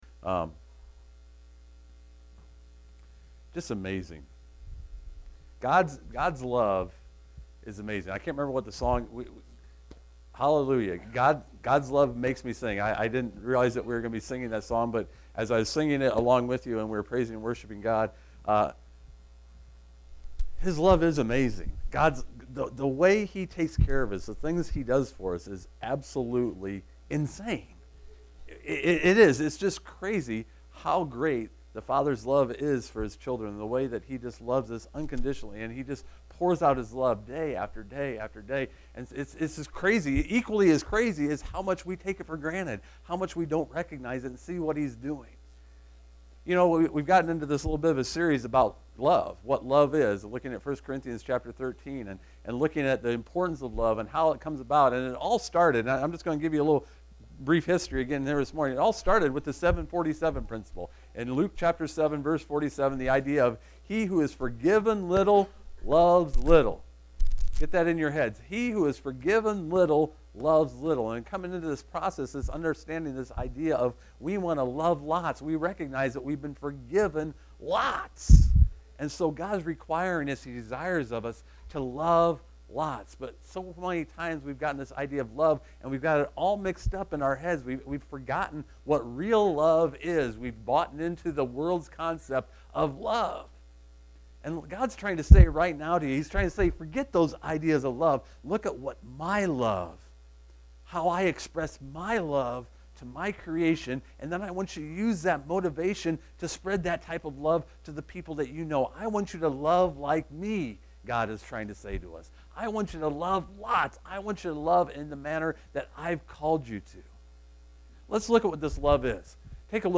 Jeremiah 9:23-24 Service Type: Sunday Morning Kindness is love with it's work clothes on.